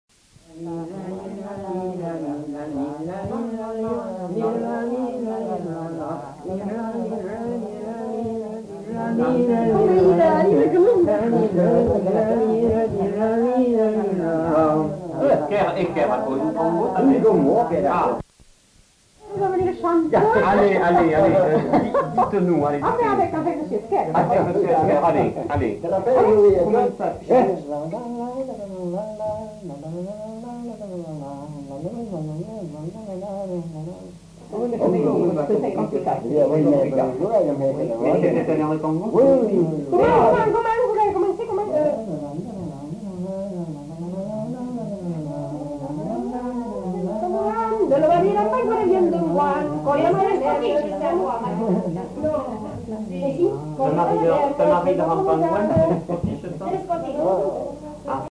Aire culturelle : Grandes-Landes
Lieu : Luxey
Genre : chant
Type de voix : voix mixtes
Production du son : fredonné
Danse : congo